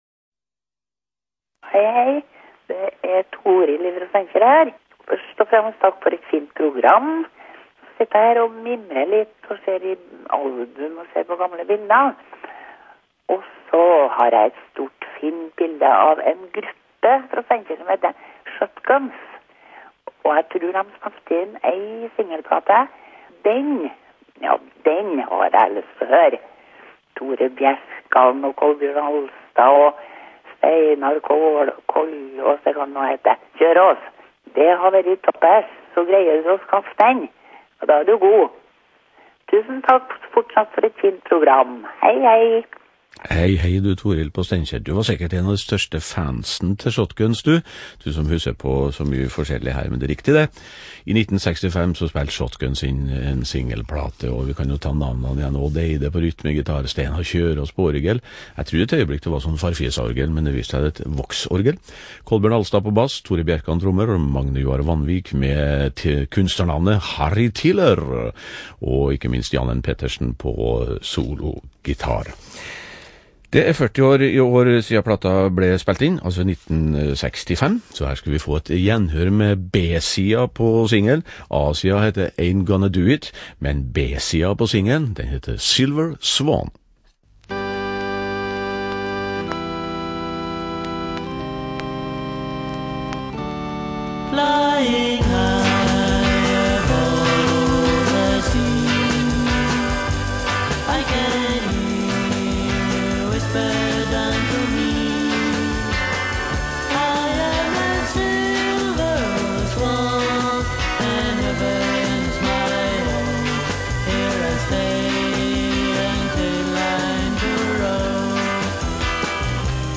(NRK - opptak)